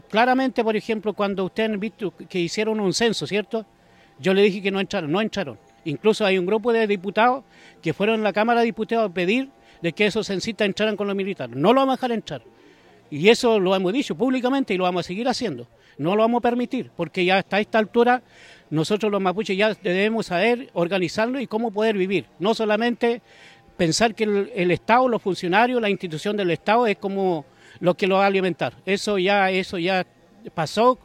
Al respecto, este lunes el dirigente mapuche reconoció en conversación con Radio Bío Bío que fue él quien le dio un “portazo” a la exministra del Interior, Izkia Siches.